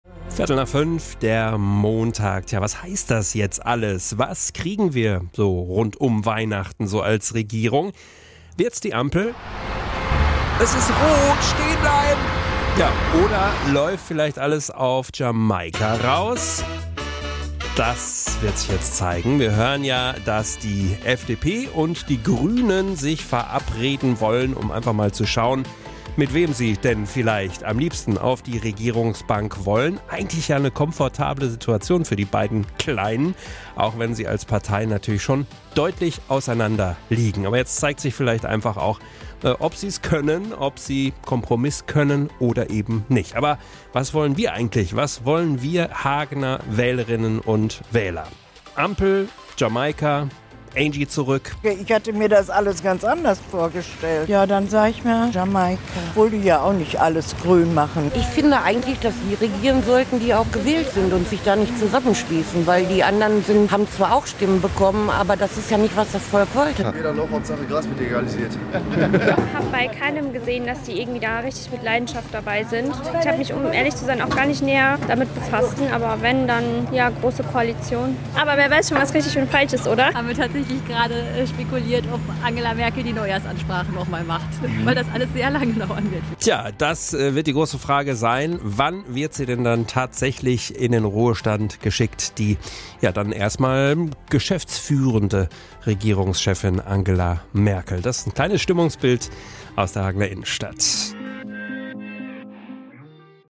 Wahlumfrage - Radio Hagen